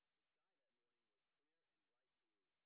sp10_white_snr10.wav